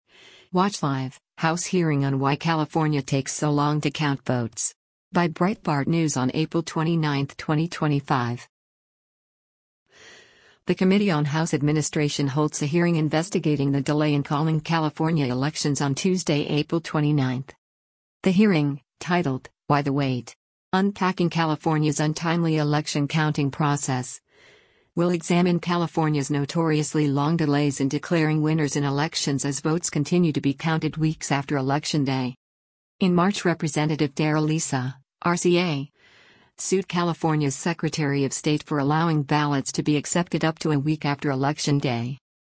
Watch Live: House Hearing on Why California Takes So Long to Count Votes
The Committee on House Administration holds a hearing investigating the delay in calling California elections on Tuesday, April 29.